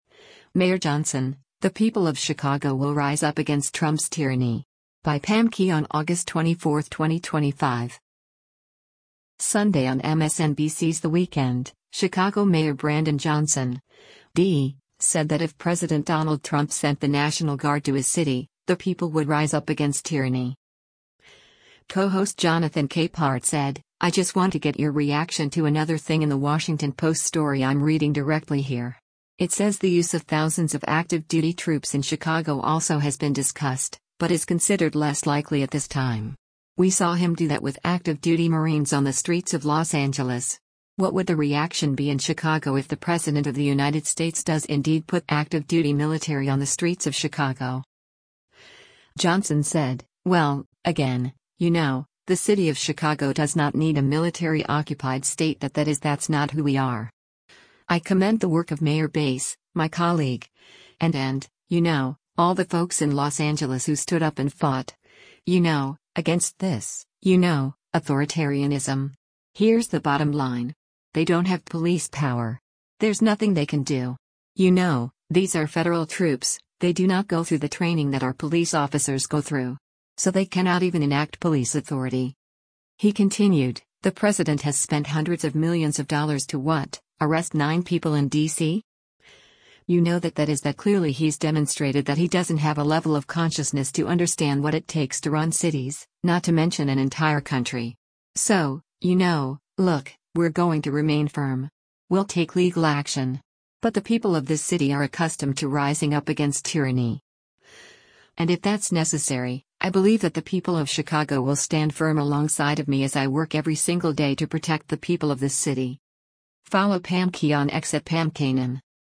Sunday on MSNBC’s “The Weekend,” Chicago Mayor Brandon Johnson (D) said that if President Donald Trump sent the National Guard to his city, the people would rise up against “tyranny.”